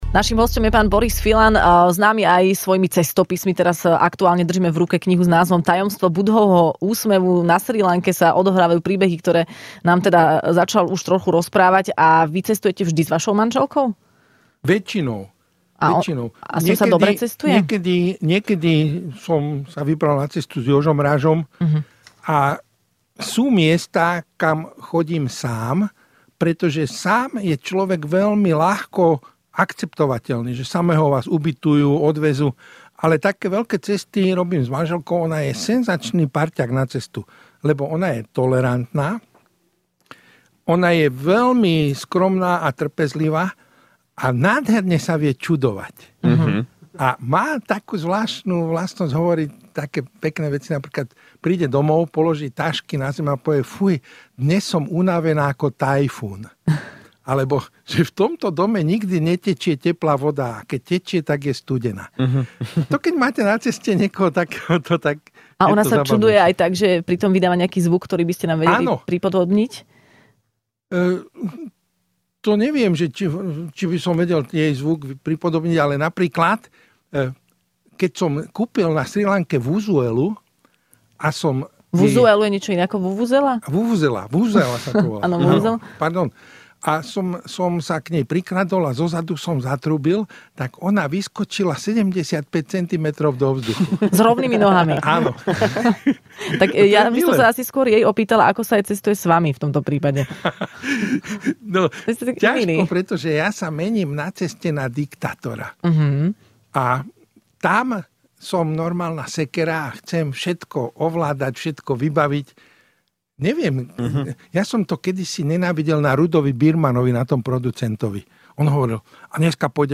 Hosťom v Rannej šou bol cestovateľ a spisovateľ Boris Filan, ktorý dostal aj ocenenie